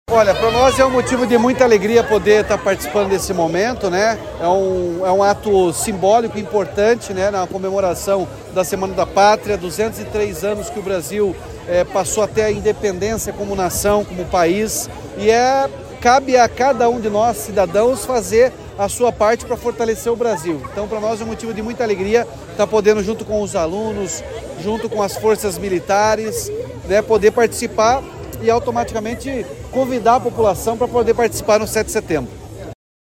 Sonora do governador Ratinho Junior sobre o início da Semana da Pátria